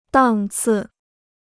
档次 (dàng cì): grade; class.